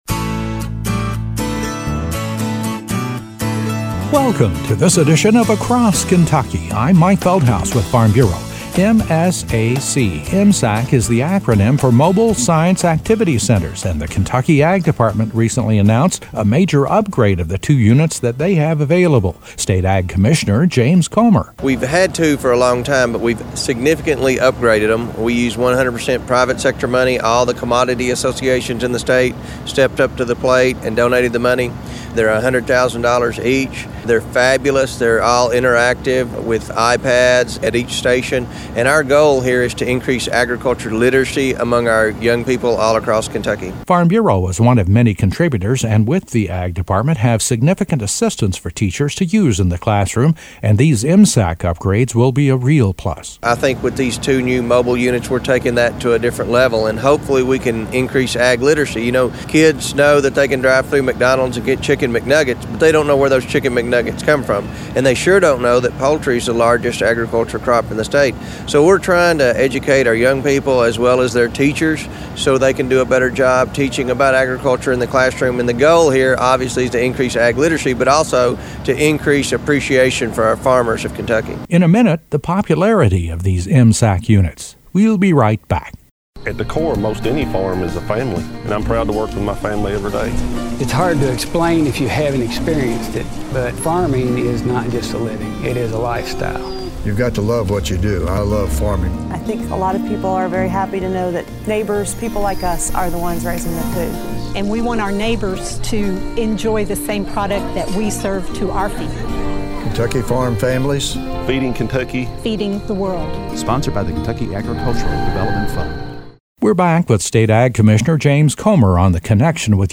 The Kentucky Ag Department has just completed a major upgrade of its two ‘mobile science activity center,’ which it makes available to the state’s teachers to help explain the importance of agriculture in the day to day lives of students and their families. State Ag Commissioner, James Comer, is featured.